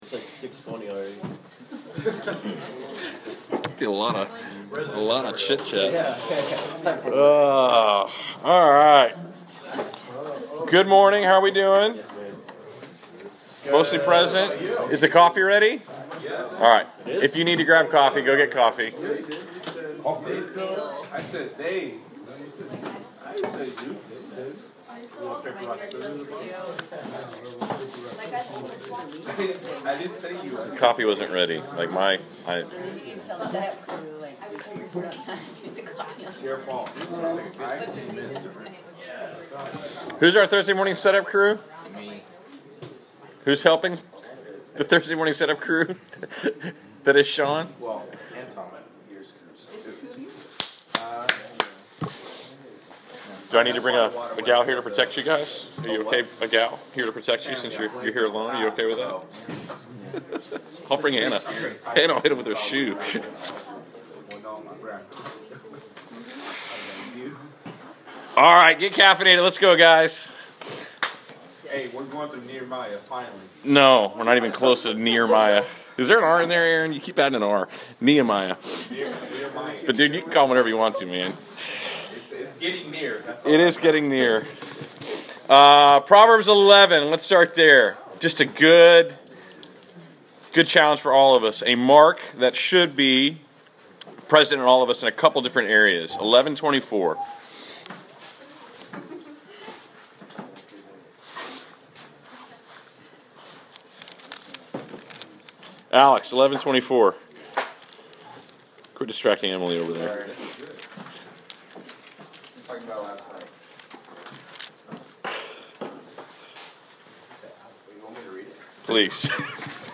Class Session Audio February 23